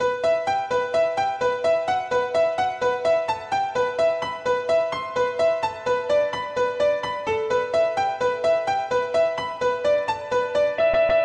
描述：用FL Studio的Nexus钢琴制作，128BPM，调B大调
Tag: 128 bpm Trap Loops Piano Loops 1.89 MB wav Key : B FL Studio